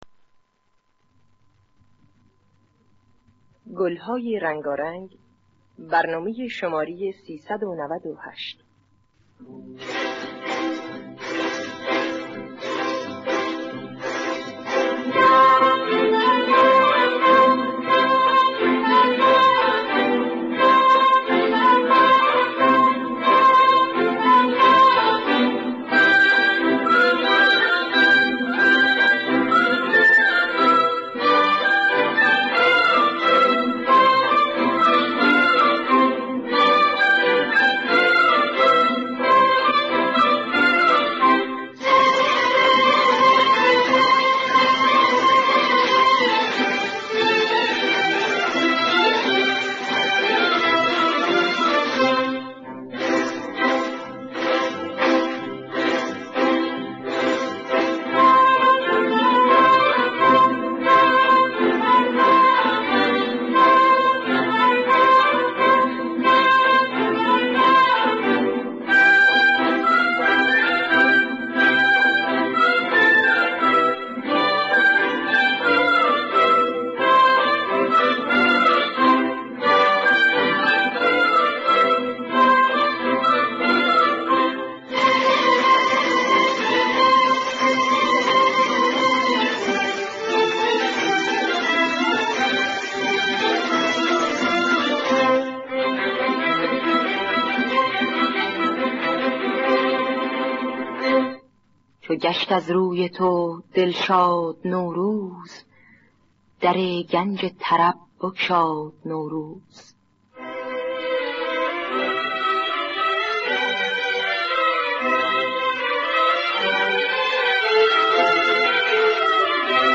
دانلود گلهای رنگارنگ ۳۹۸ با صدای سیما بینا، عبدالوهاب شهیدی در دستگاه ماهور.
خوانندگان: سیما بینا عبدالوهاب شهیدی نوازندگان: جواد معروفی